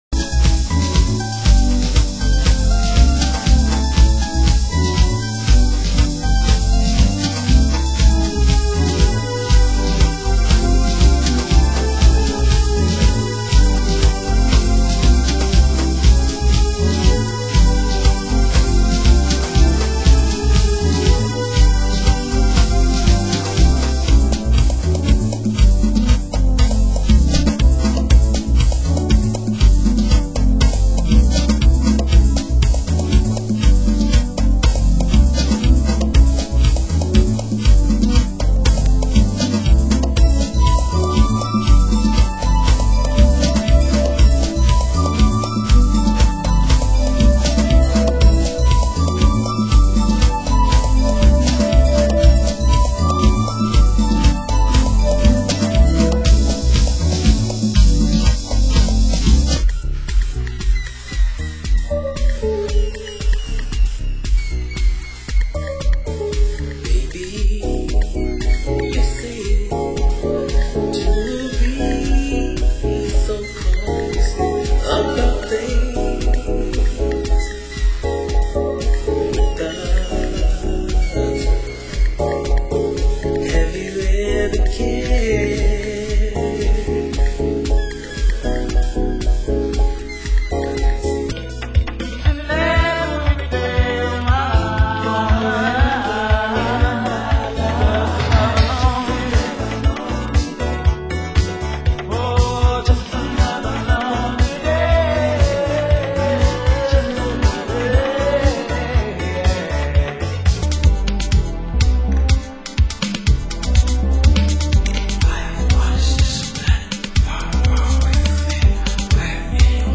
Genre: Chicago House